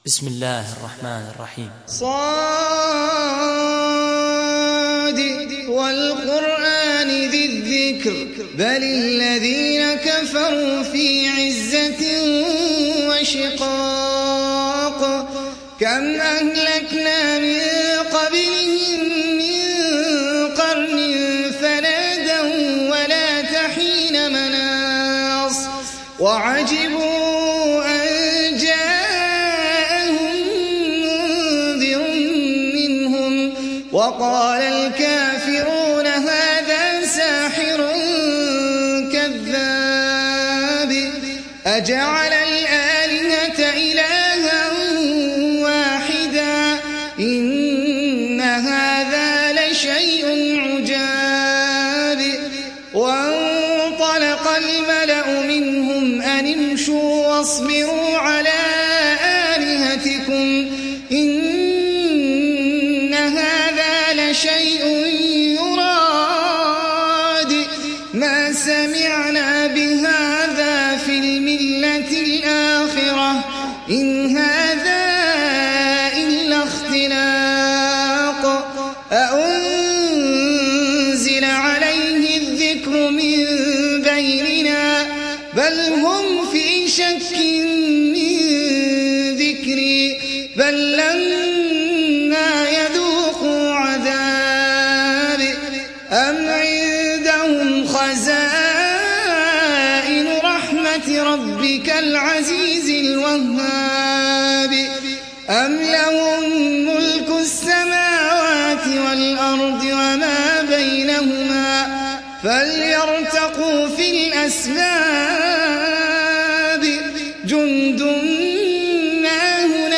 تحميل سورة ص mp3 بصوت أحمد العجمي برواية حفص عن عاصم, تحميل استماع القرآن الكريم على الجوال mp3 كاملا بروابط مباشرة وسريعة